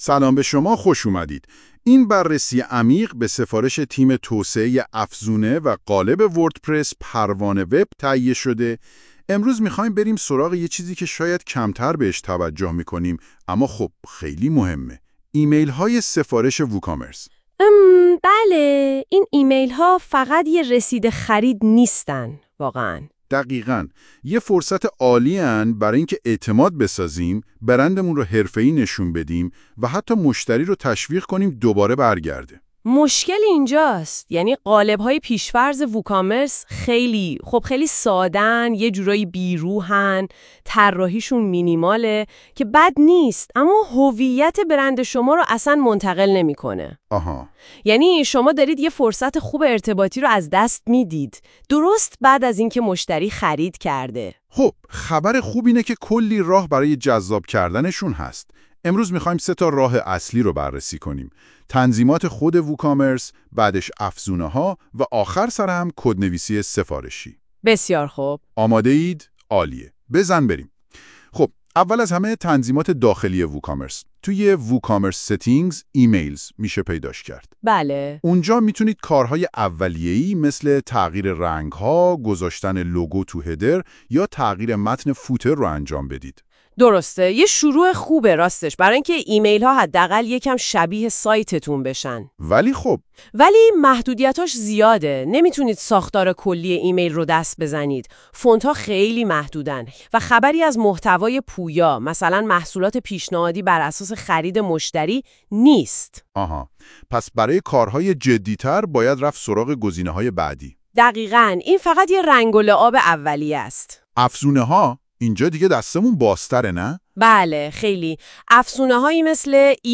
قبل از شروع مقاله ، اگه حوصله یا فرصت مطالعه این مقاله رو ندارید ، پیشنهاد می کنیم پادکست صوتی زیر که با ابزار هوش مصنوعی (گوگل notebooklm ) به زبان فارسی توسط تیم پروان وب تولید شده است ، گوش کنید.